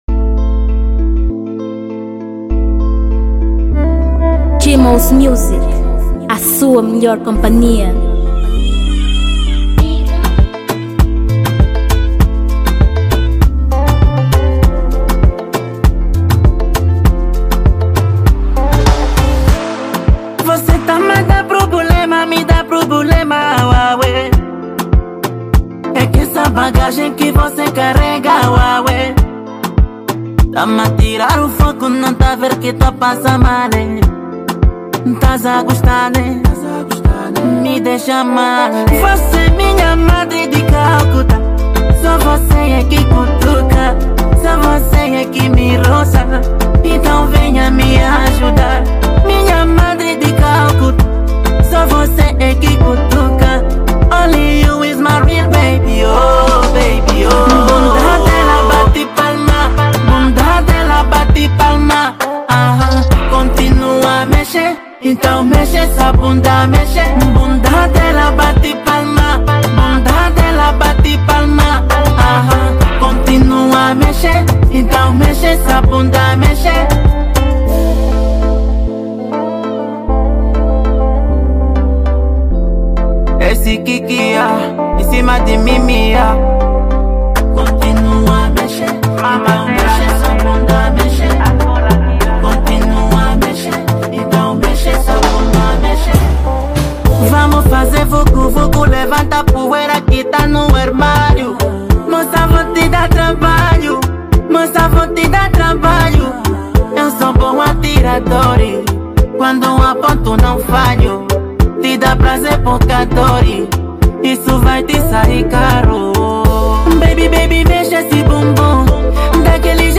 2023 Gênero: Dancehall Tamanho